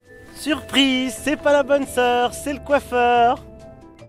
Sound Effects drama , wonder , surprise , mystery